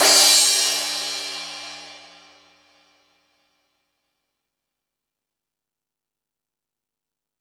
Metal Drums(43).wav